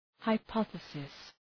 Προφορά
{haı’pɒɵısıs}